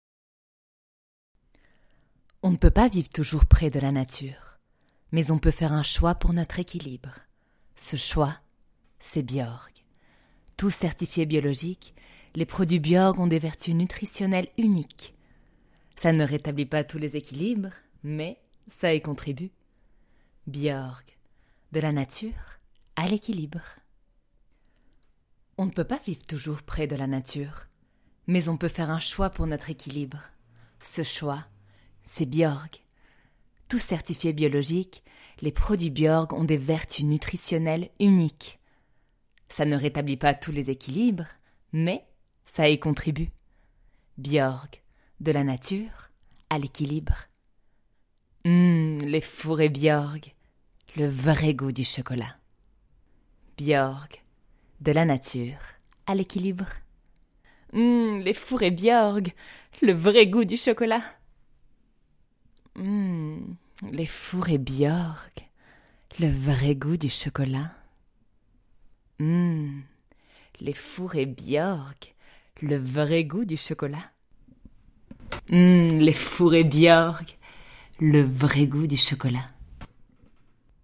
Publicité en français
Voix off
21 - 48 ans